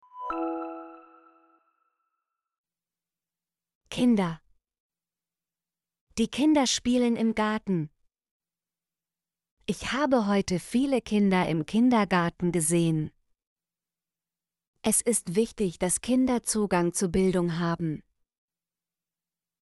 kinder - Example Sentences & Pronunciation, German Frequency List